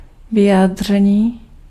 Ääntäminen
IPA: [pa.ʁɔl]